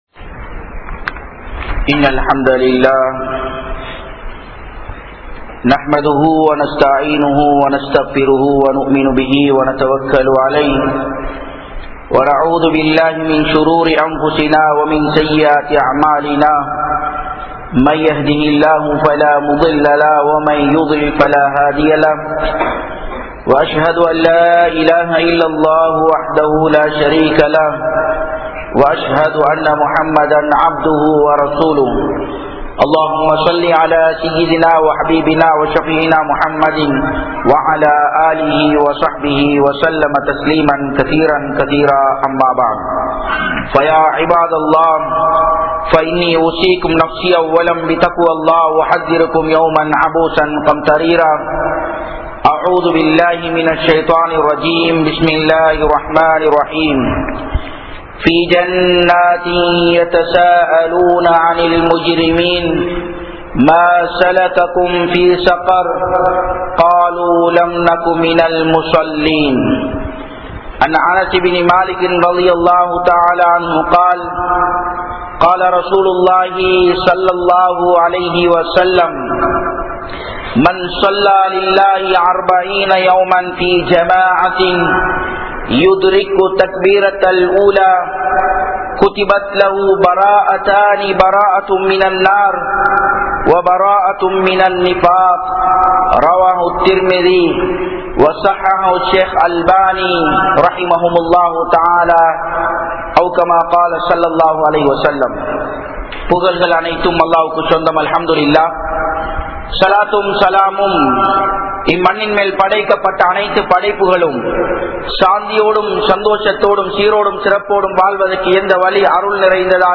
Narahaththai Vittu Thadukkum Amal (நரகத்தை விட்டும் தடுக்கும் அமல்) | Audio Bayans | All Ceylon Muslim Youth Community | Addalaichenai